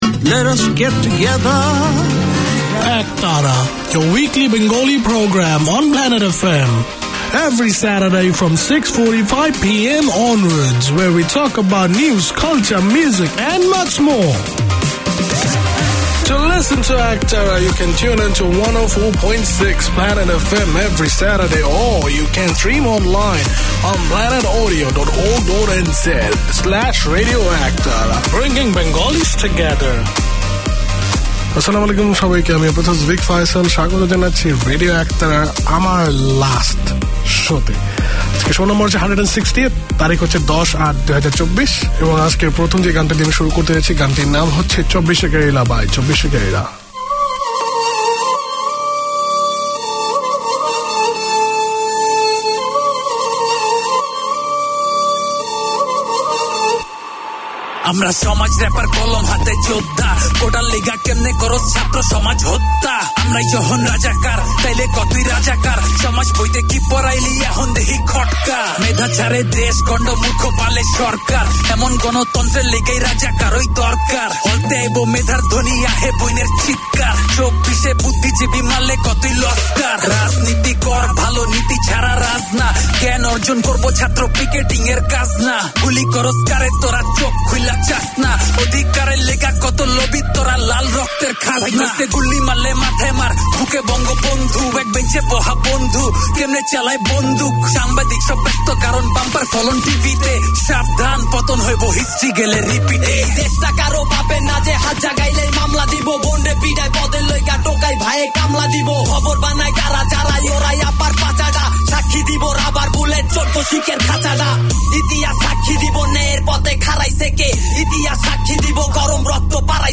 Produced by and for the Bangladeshi community in Auckland, Radio Ektara features current affairs, community notices, music and interviews to entertain and inform.
Community magazine